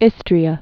(ĭstrē-ə)